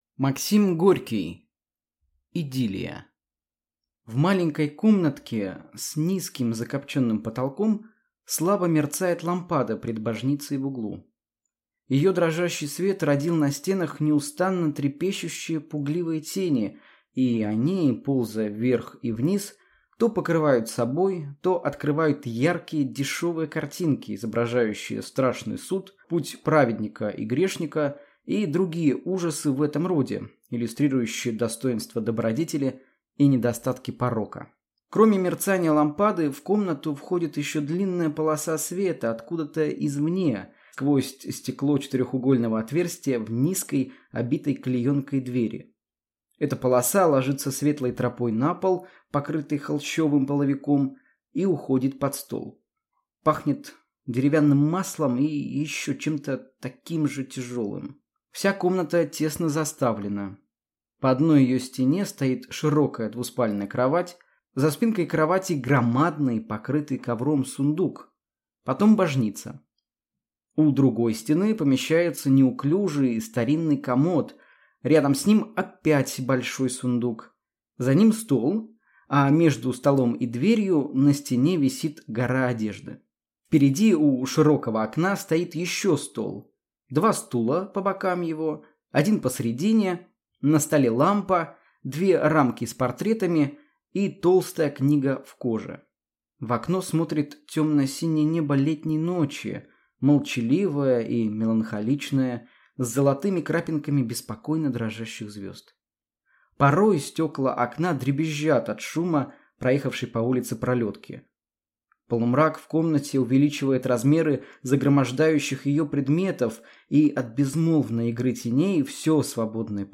Аудиокнига Идиллия | Библиотека аудиокниг